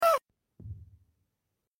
AAAHHH sound effects free download